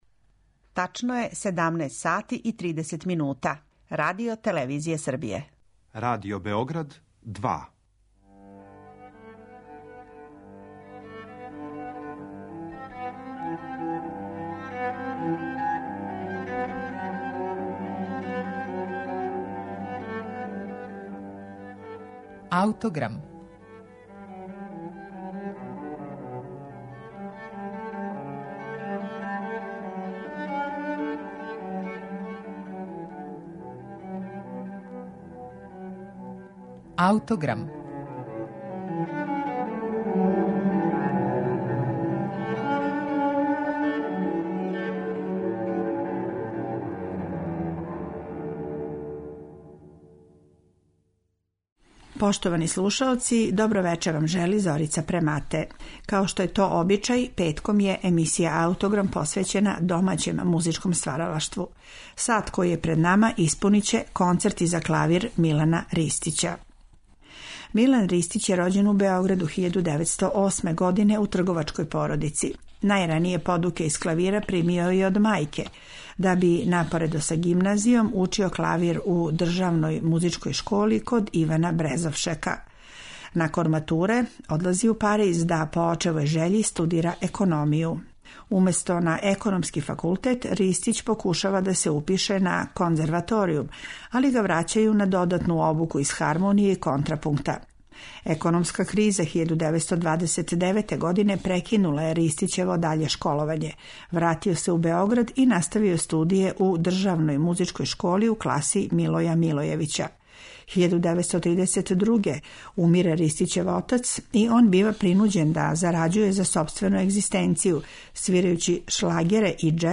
Ристићев стил биће сагледан преко његових концерата, а емисију ће започети Први концерт за клавир, настао 1954. године.